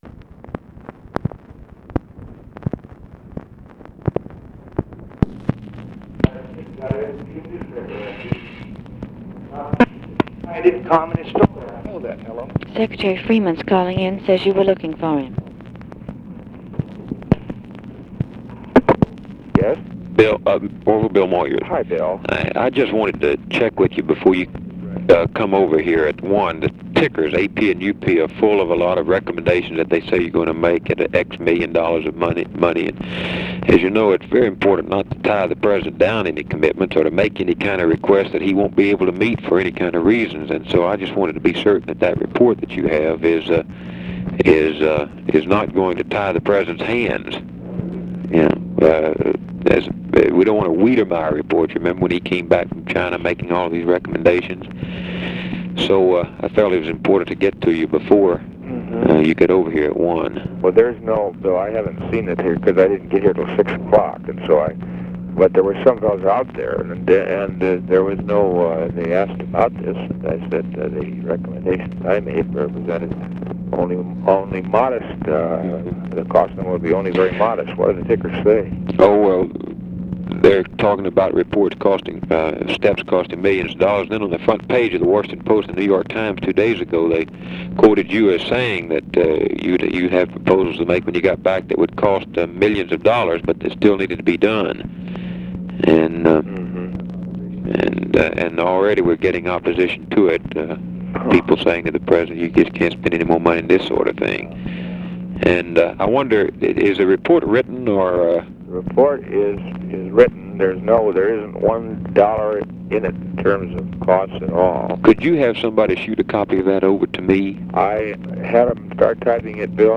Conversation with ORVILLE FREEMAN, OFFICE CONVERSATION and BILL MOYERS, February 16, 1966
Secret White House Tapes